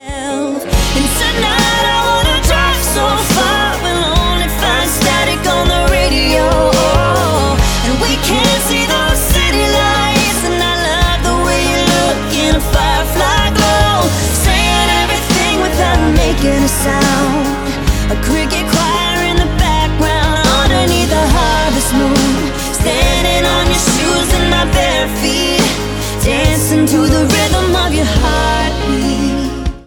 • Country
a country pop and a R&B song